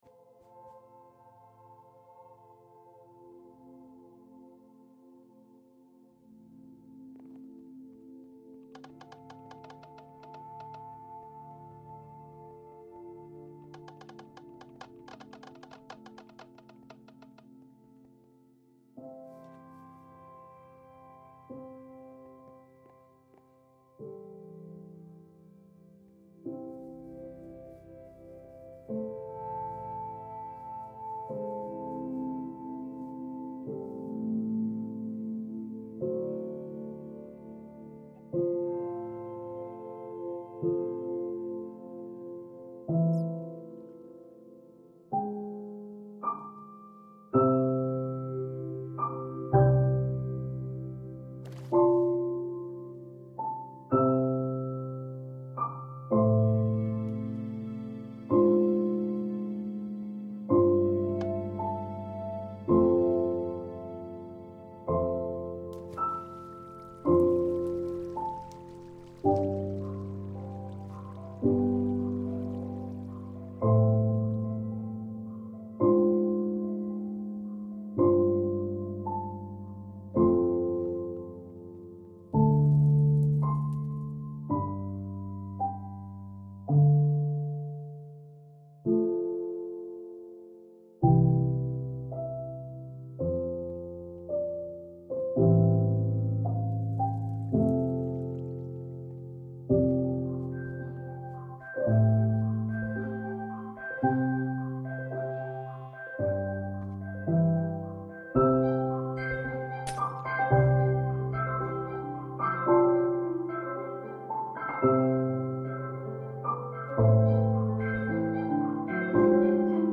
I enter the game, and a music track start